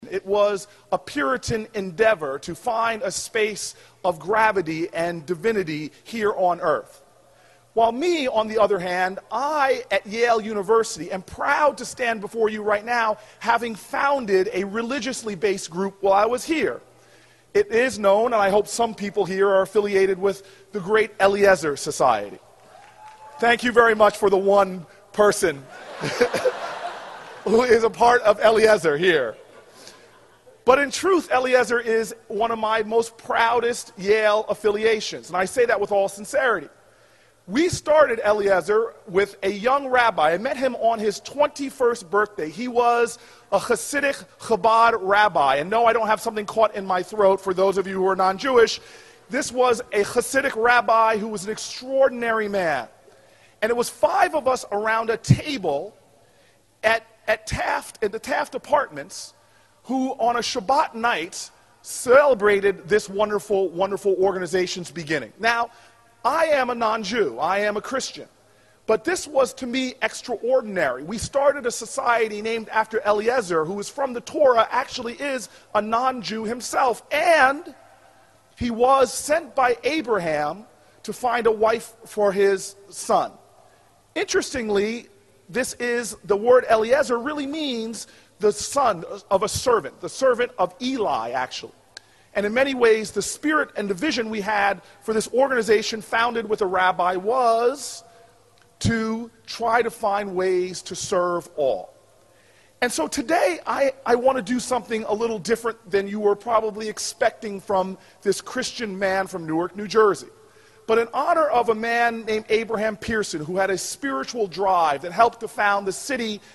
公众人物毕业演讲第435期:科里布克2013年耶鲁大学(3) 听力文件下载—在线英语听力室